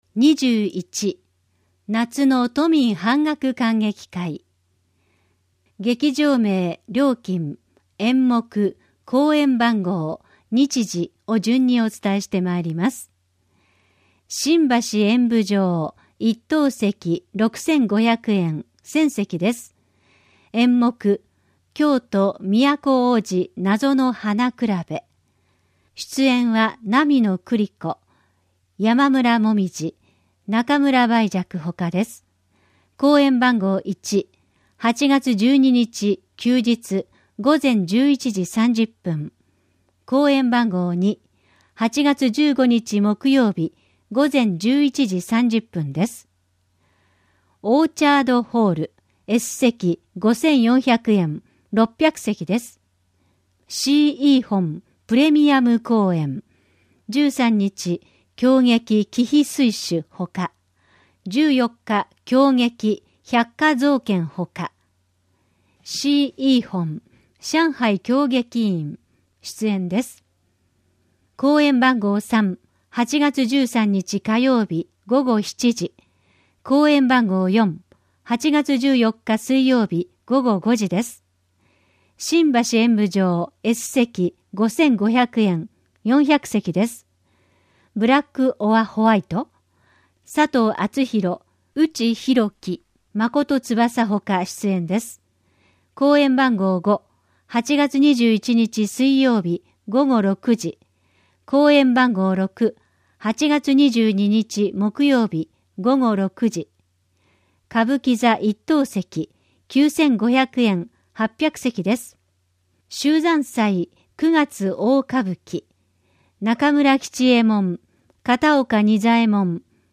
「広報東京都音声版」は、視覚に障害のある方を対象に「広報東京都」の記事を再編集し、音声にしたものです。